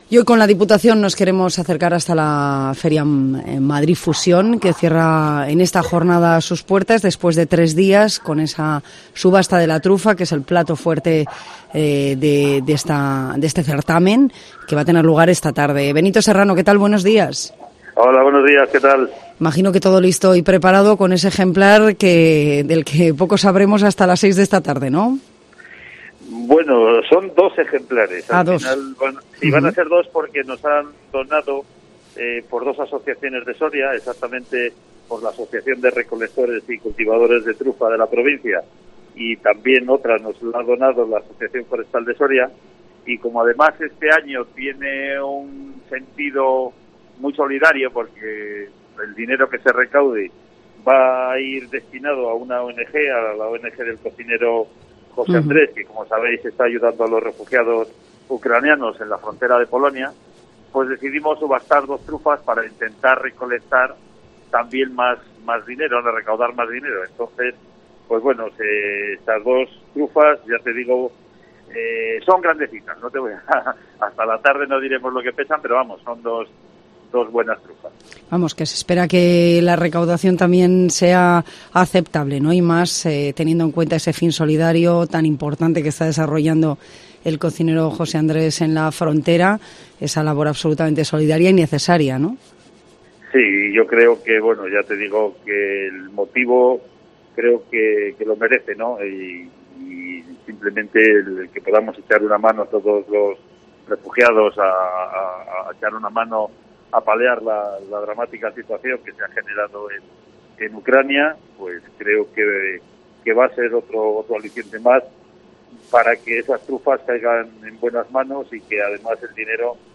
BENITO SERRANO HABLA EN COPE DE LA PRESENCIA DE SORIA EN MADRID FUSIÓN